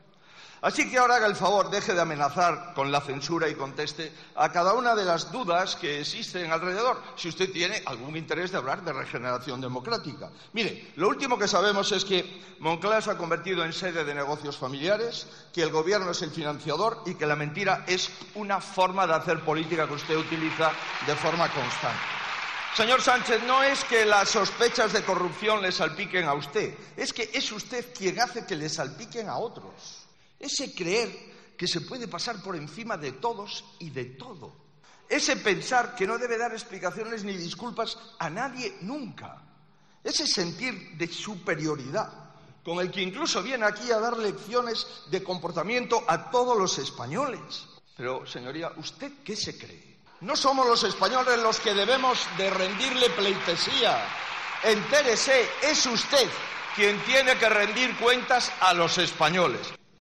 "El mayor bulo de España es usted", le ha dicho Feijóo a Sánchez desde la tribuna de oradores del Congreso, tras exponer este miércoles el jefe del Ejecutivo su plan de regeneración, con el que asegura pretende parar los bulos y evitar que los medios tengan más financiadores que lectores y no se puedan comprar "tabloides".